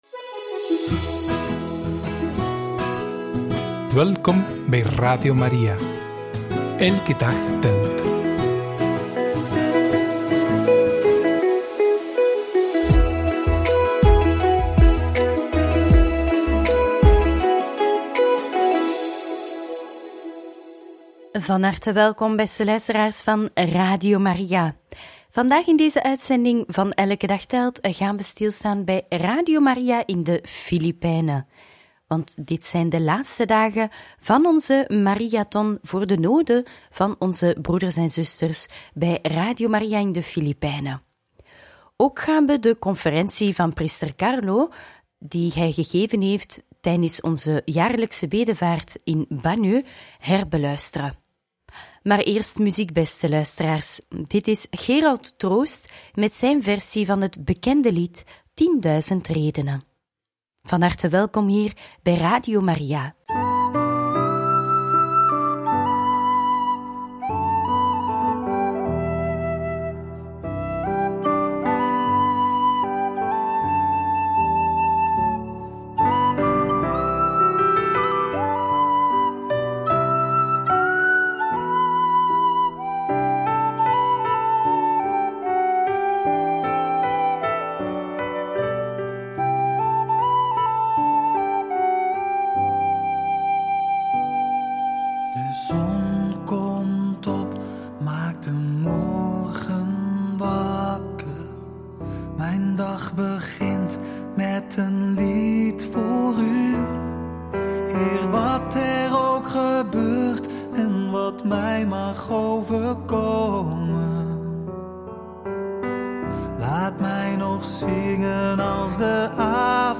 Radio Maria Podcast Over het heiligdom van Sint Jozef van Mandaue - 'We zijn koningen, profeten en priesters': conferentie